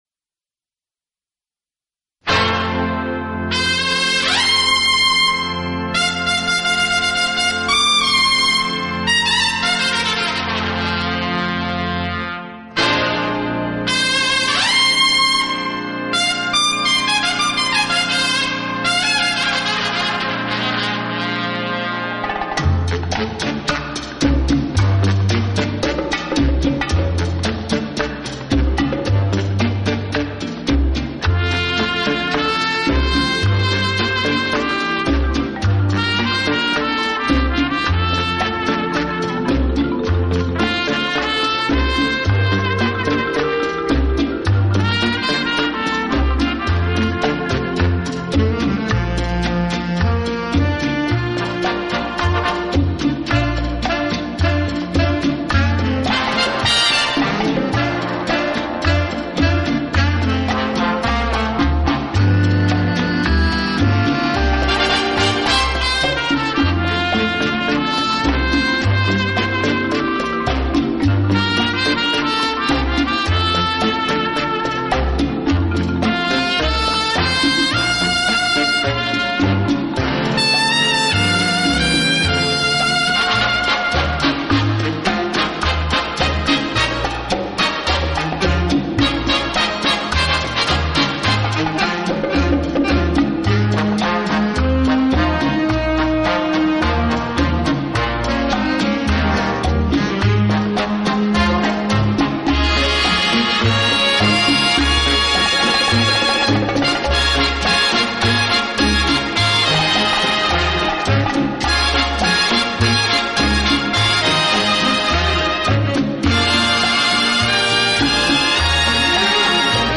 Genre: Orchestra jazz | Latin jazz